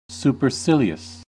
Ääntäminen
IPA : /ˌsuː.pə(ɹ)ˈsɪ.li.əs/ IPA : /ˌsjuː.pə(ɹ)ˈsɪ.li.əs/